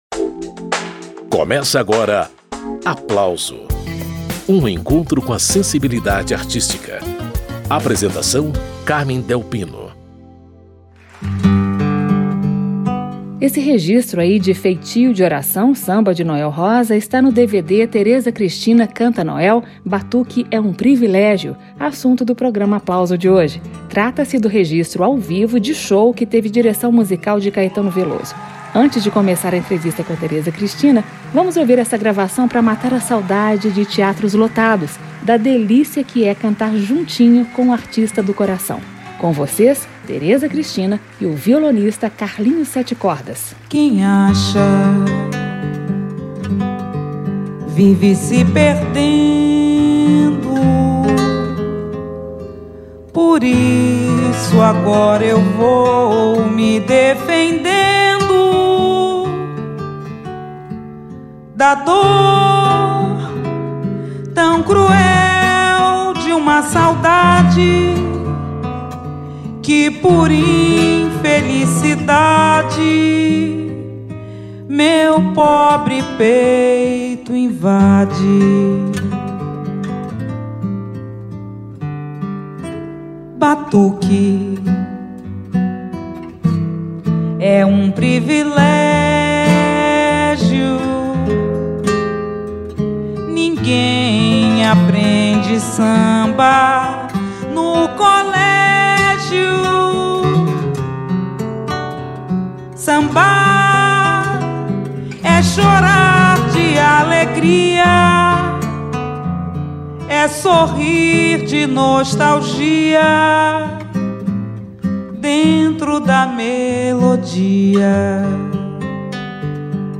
A rainha das lives, Teresa Cristina, participa desta edição do programa Aplauso.